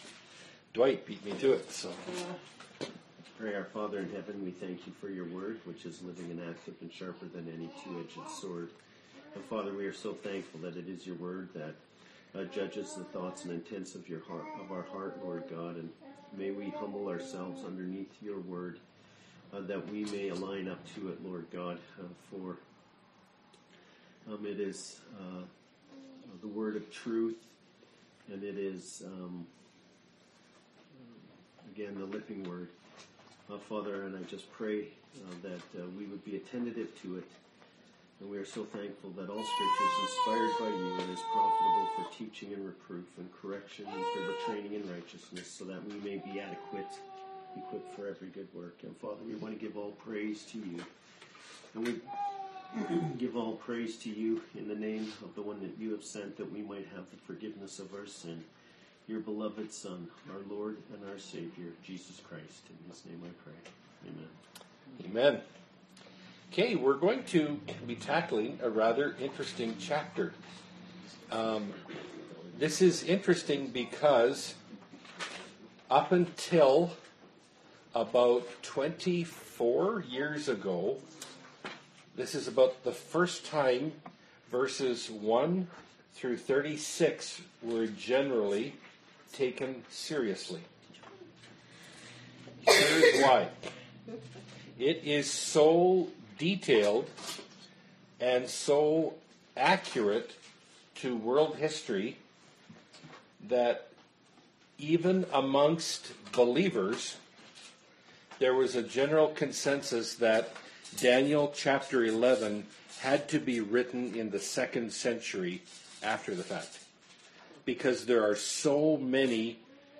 Bible Study – Daniel 11 – Part 1 of 2 (2017)